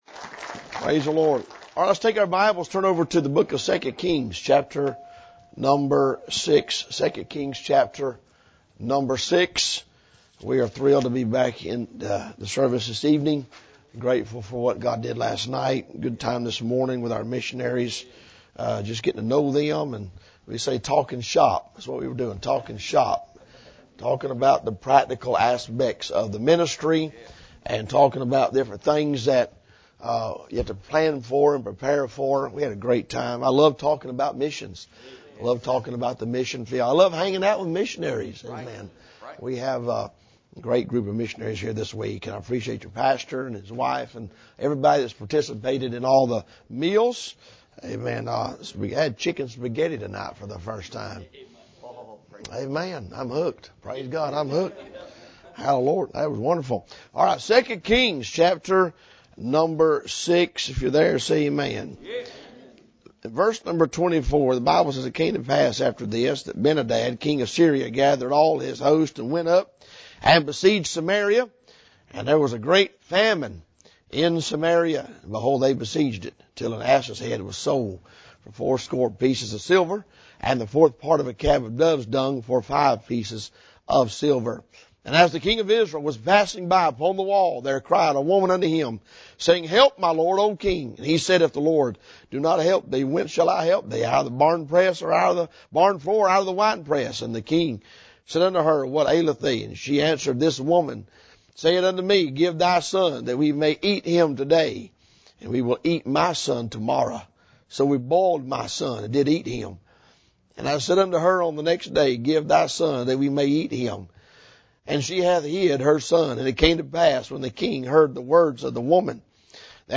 This sermon calls Christians to wake up, move out of their comfort zones, and use their time, resource